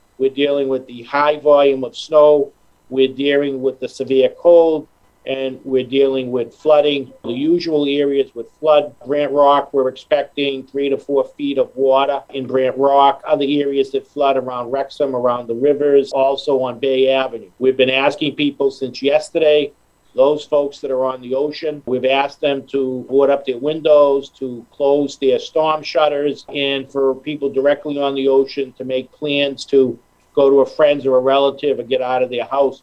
Town Administrator Michael Maresco says residents should prepare while they can.